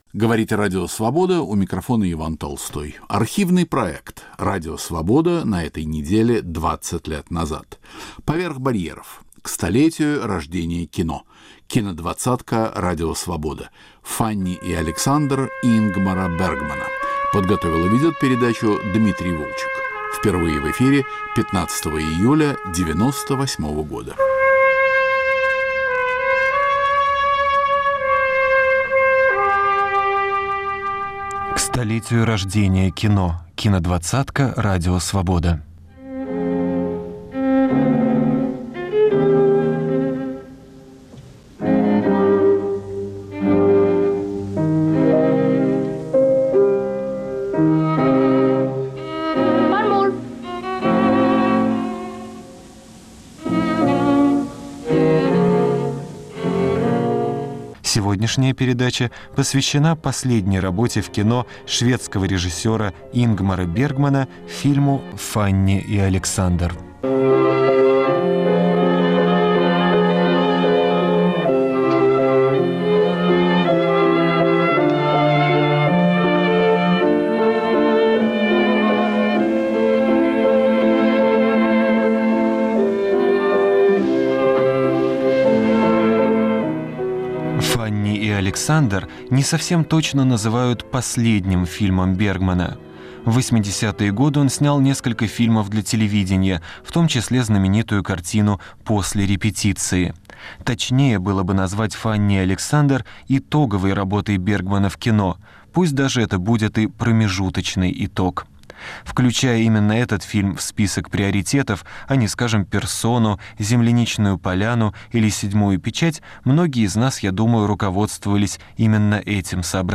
Архивный проект.